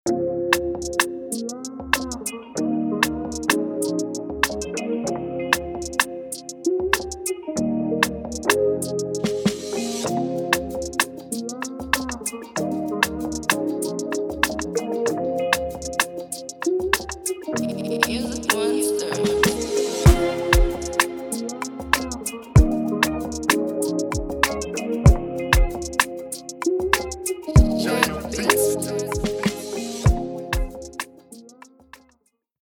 Tempo: 96
Genre: Afrobeats, Afropop, Afro-rave